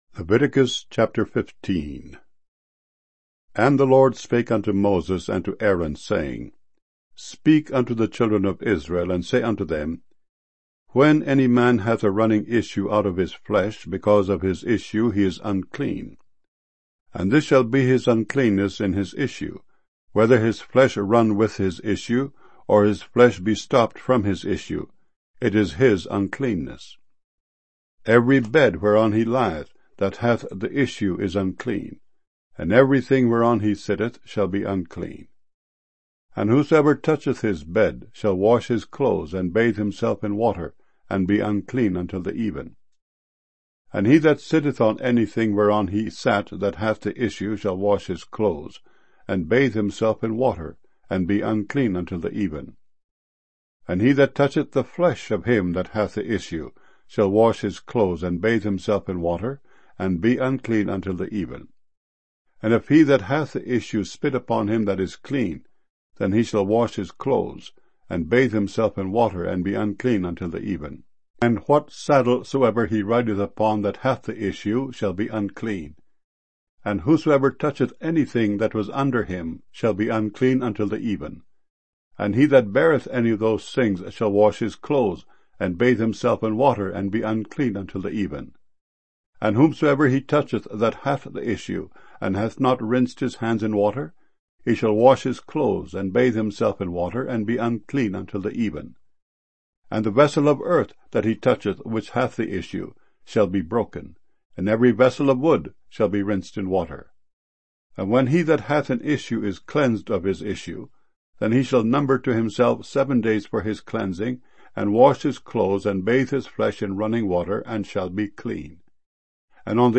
00 Streaming MP3 Audio Bible files mono 32 kbs small direct from wav files